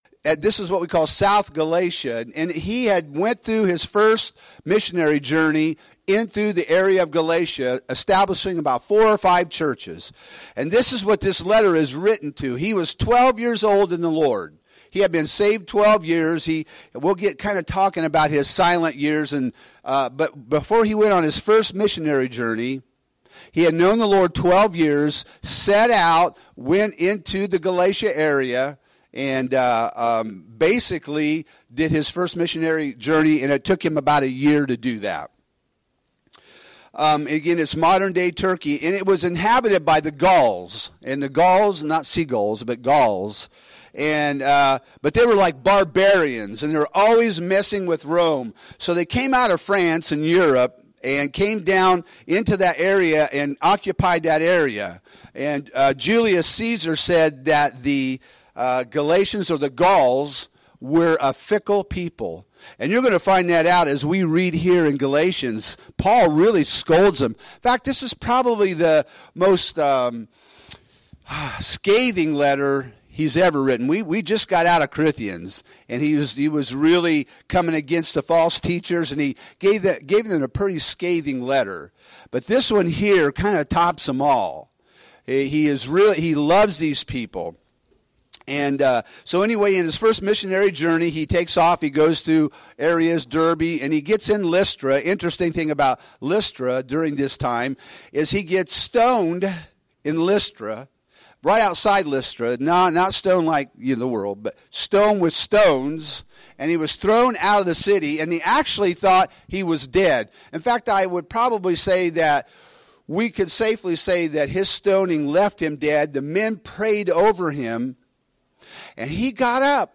Home › Sermons › Galatians 1